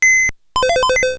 TurnOn.wav